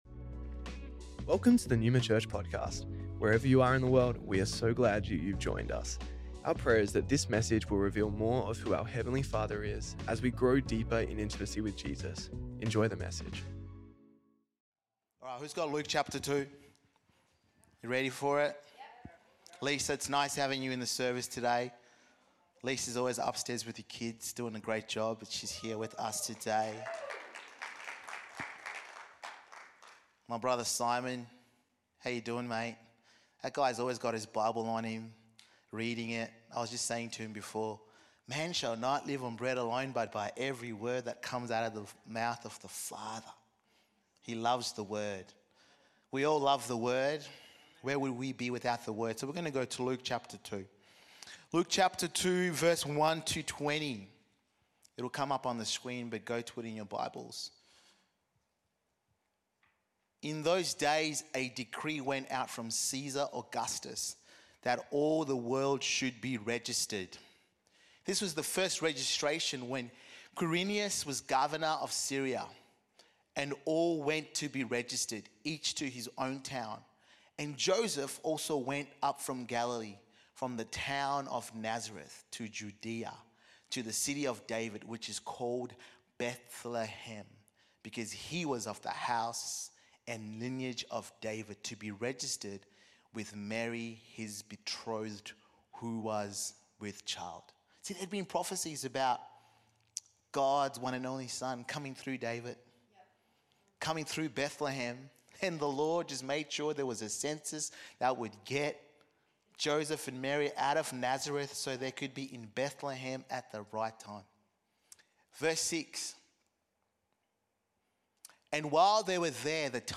Neuma Church Melbourne South Originally Recorded at the 10AM Service on Sunday 22nd December 2024